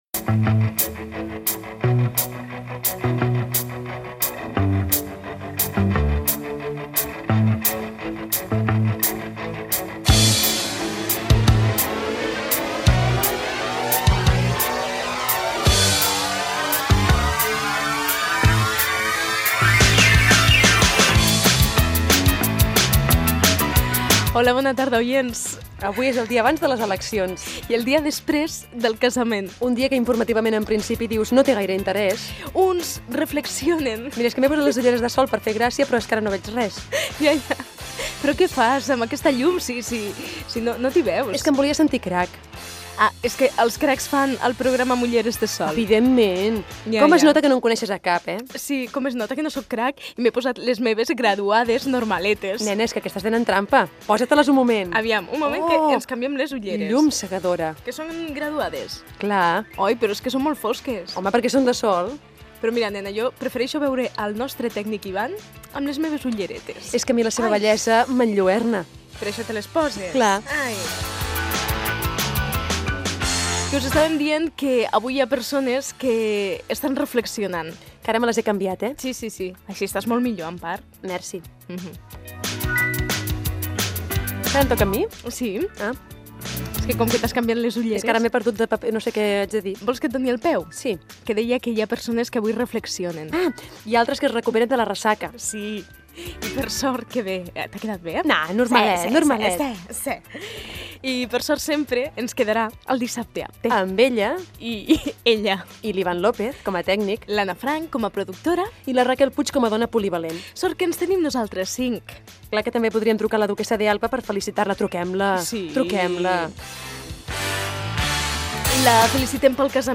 Comentari sobre les ulleres de sol i sobre el casament d'Eugenia Martínez de Irujo i Fran Rivera. Trucada al telèfon del palau de la Duquesa de Alba.
Entreteniment